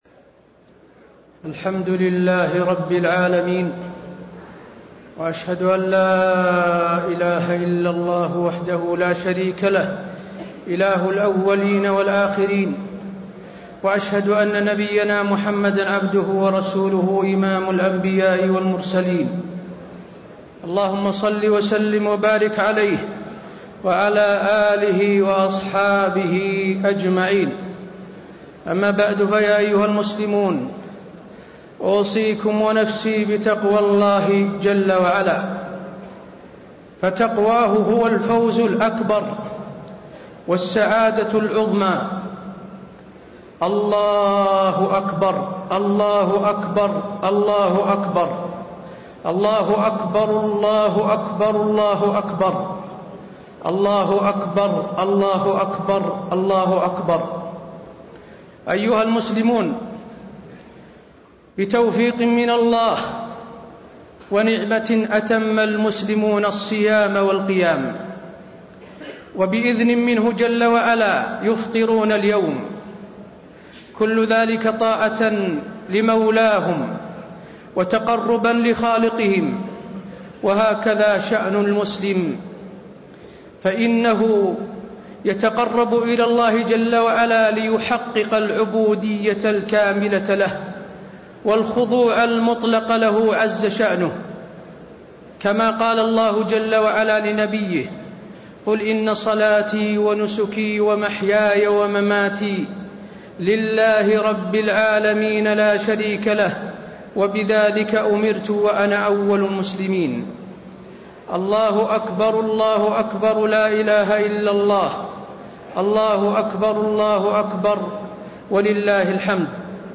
خطبة عيد الفطر- المدينة - الشيخ حسين آل الشيخ - الموقع الرسمي لرئاسة الشؤون الدينية بالمسجد النبوي والمسجد الحرام
المكان: المسجد النبوي